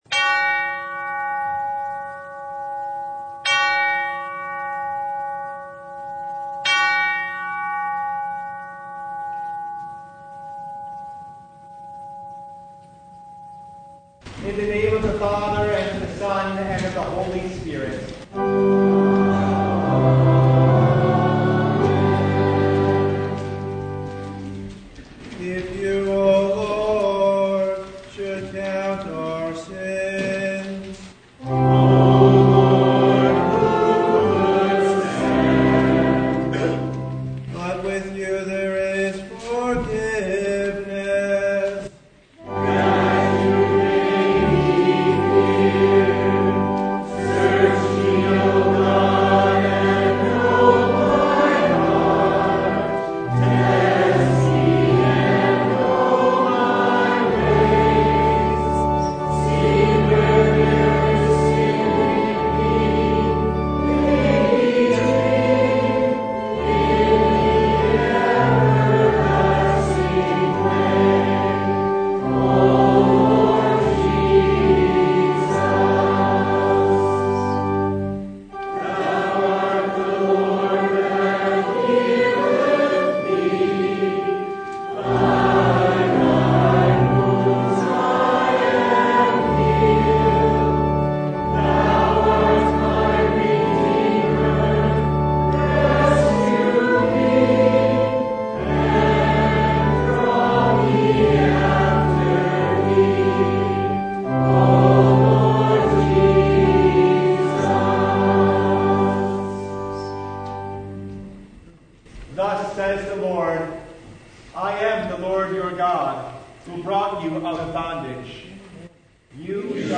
John 9:1-41 Service Type: Sunday What do we do with a man born blind?